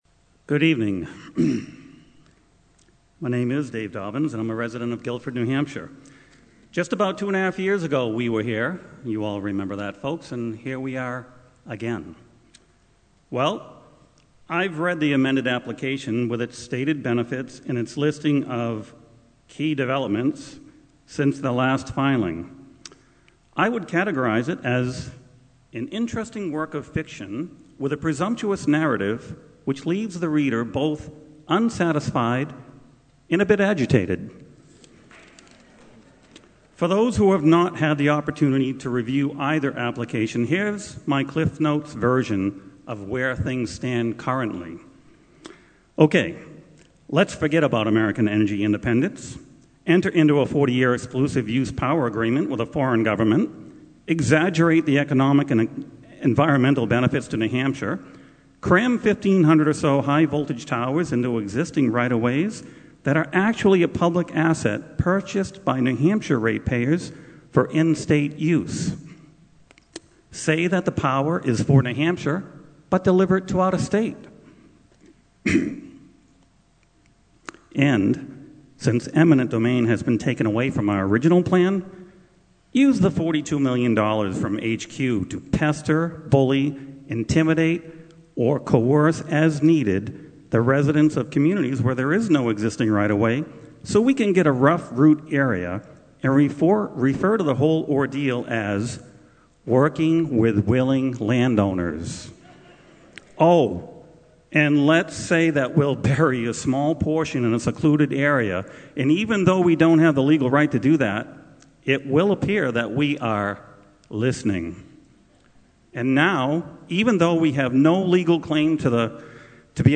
The U.S. Dept. of Energy held the second of a series of Public Scoping Meetings on the Northern Pass Project. They held their second one in Plymouth. There were approximately 675 in attendance.